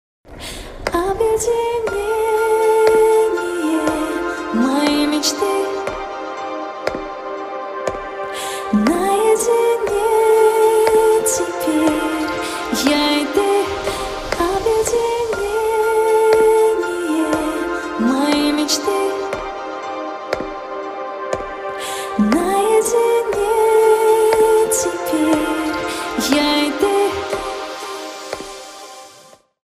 • Качество: 320, Stereo
спокойные
Спокойный припев песни, под ритмичный звук шагов.